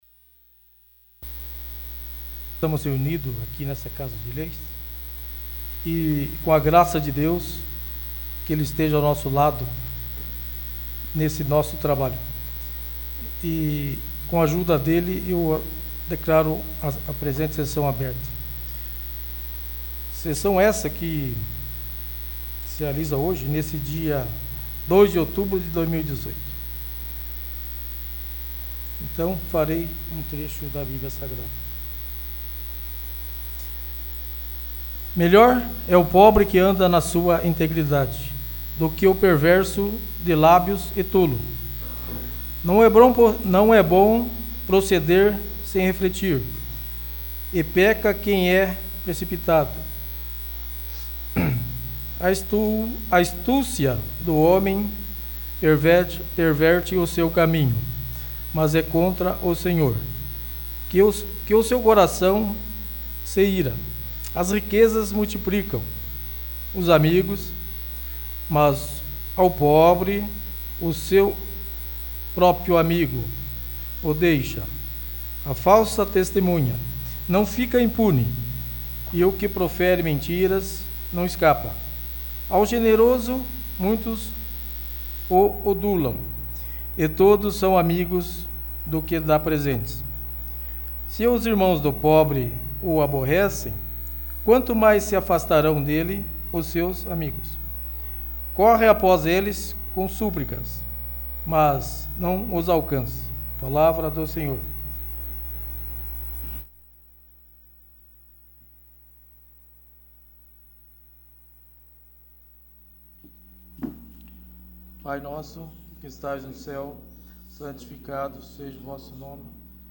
29º. Sessão Ordinária